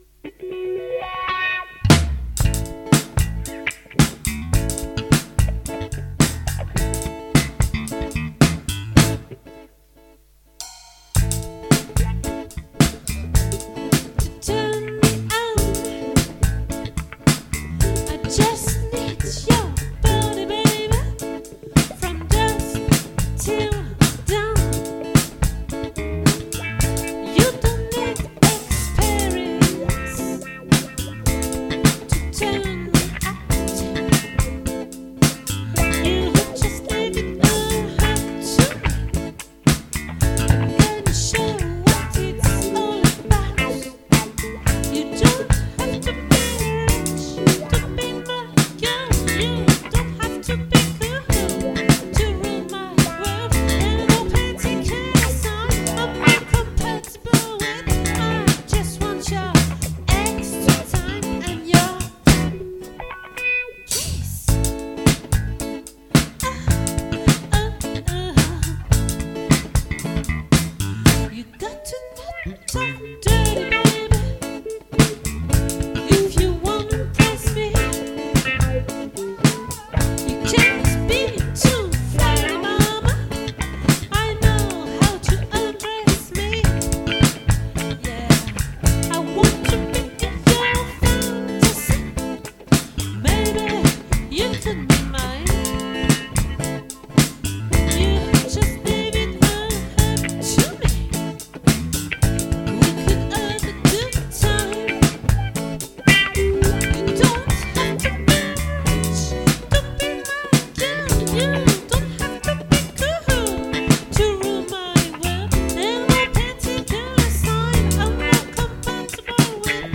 🏠 Accueil Repetitions Records_2023_03_29_OLVRE